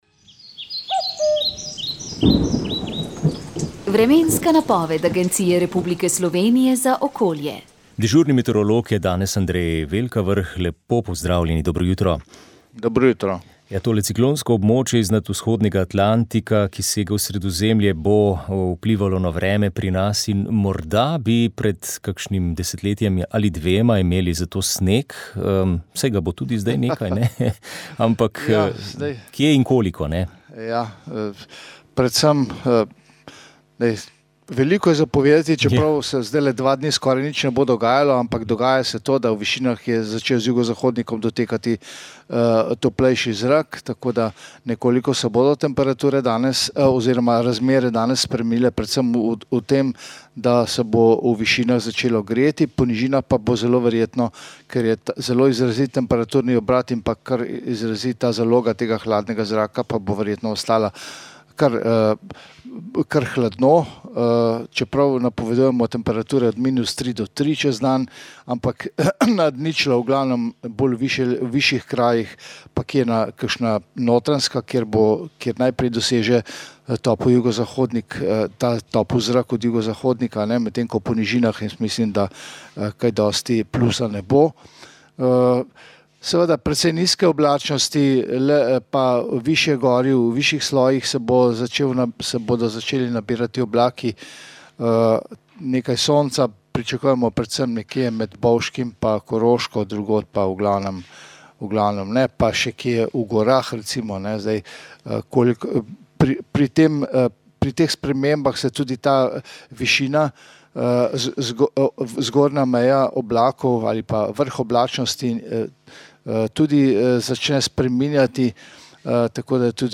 Rožni venec
Molitev je vodil vojaški vikar Matej Jakopič.